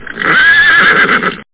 HORSE.mp3